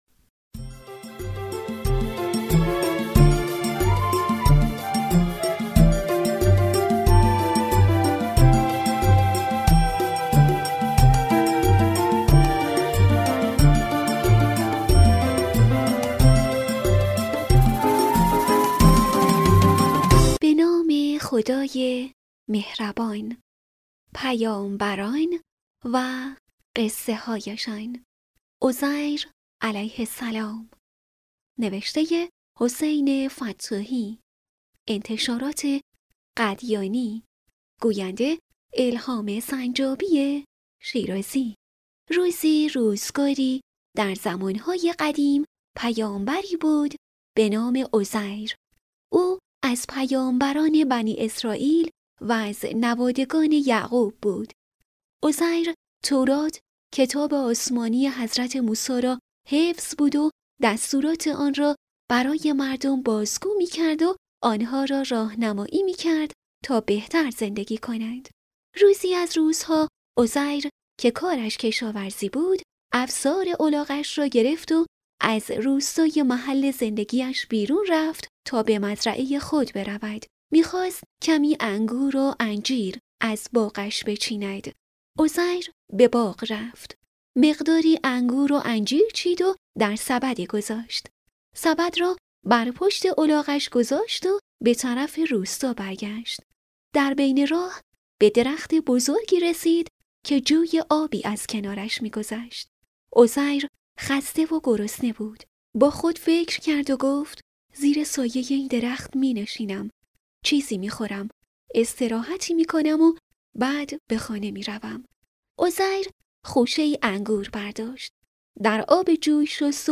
کتاب صوتی «پیامبران و قصه‌هایشان»